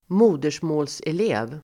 Uttal: [²m'o:der_små:l]